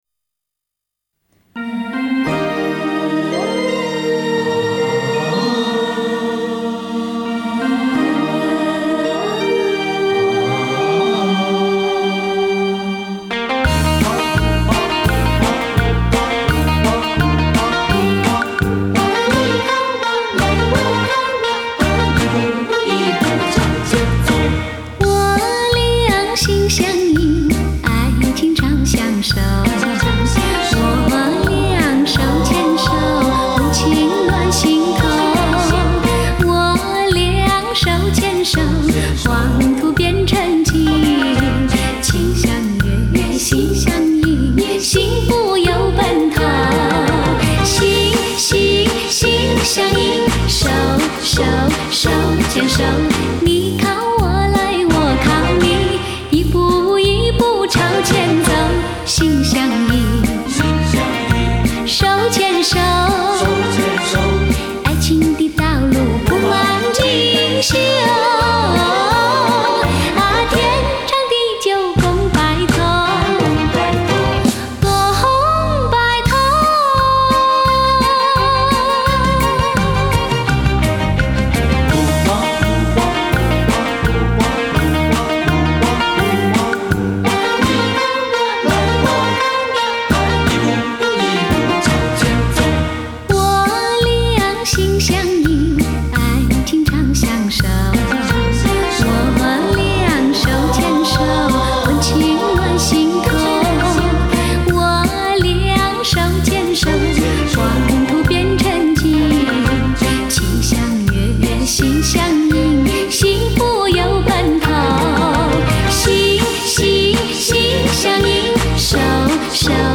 Жанр: Chinese pop